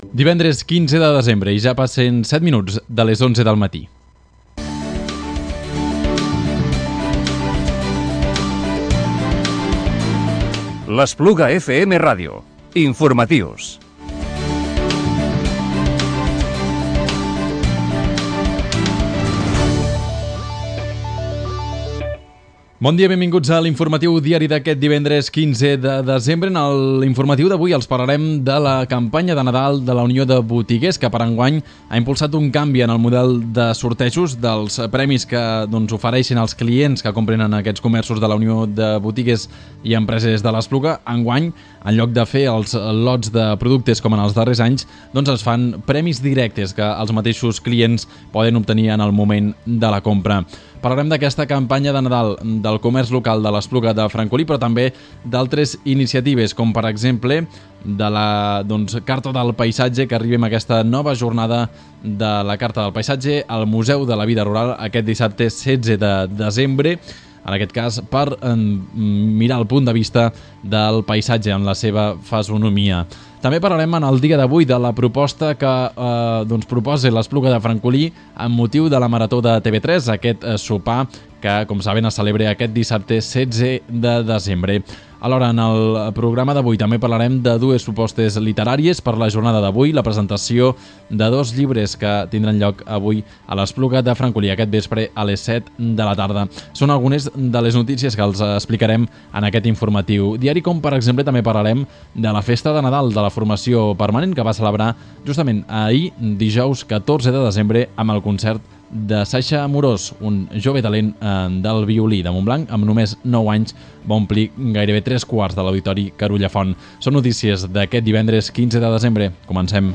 Informatiu Diari del divendres 15 de desembre del 2017